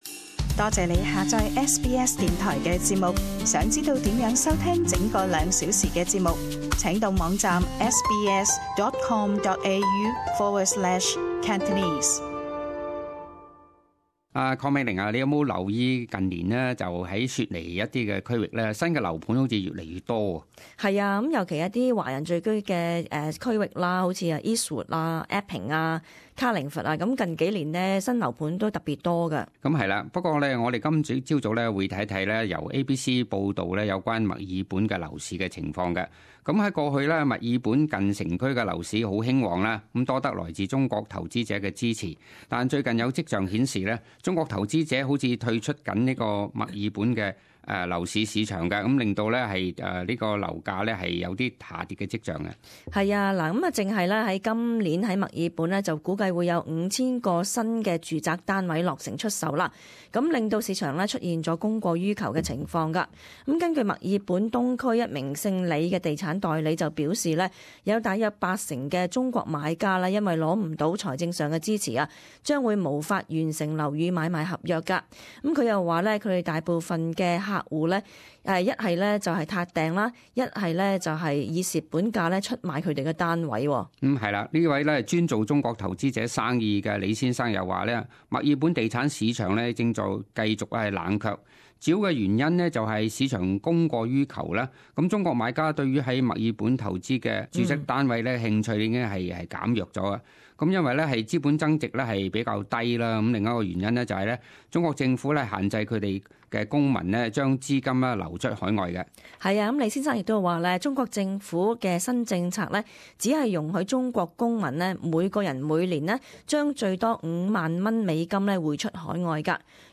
时事报导;中国投资者正退出墨尔本雅柏文地产市场。